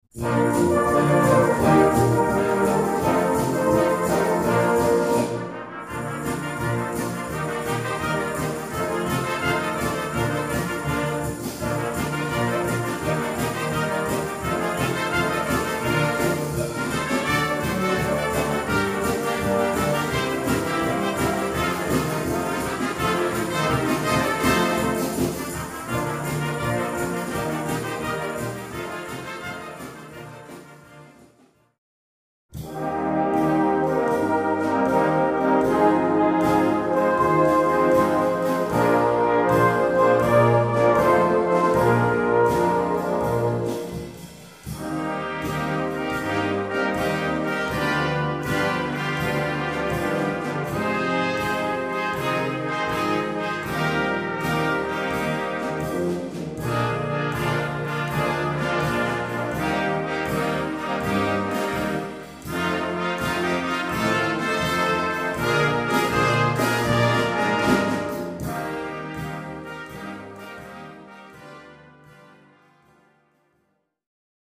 3 Trompettes et Concert Band ou Harmonie ou Fanfar